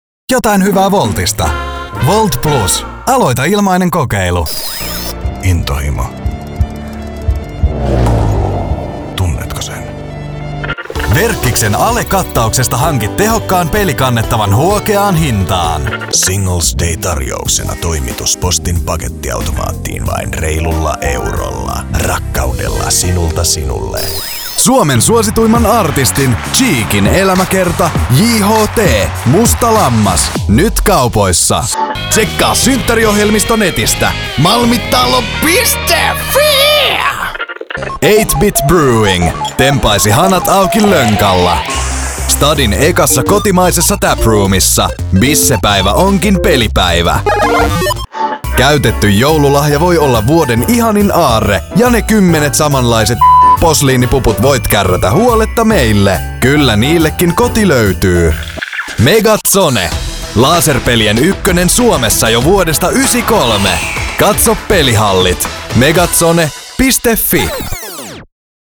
Junge, Cool, Corporate
Kommerziell